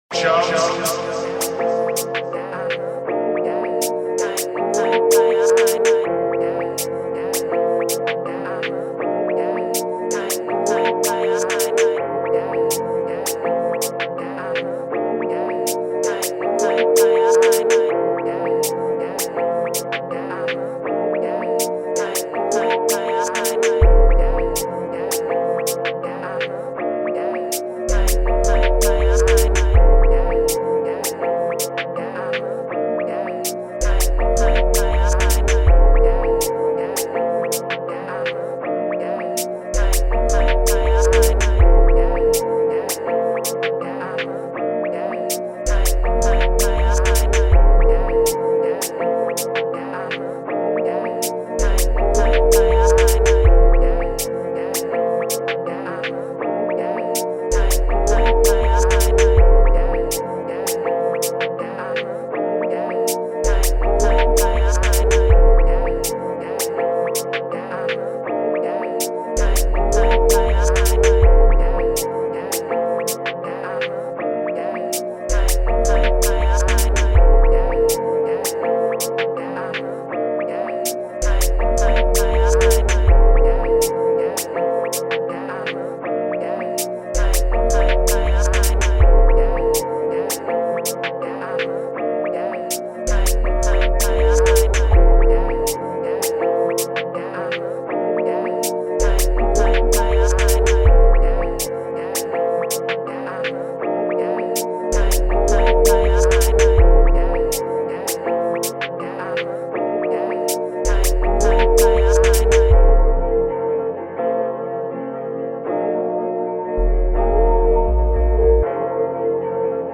official instrumental
2025 in NY Drill Instrumentals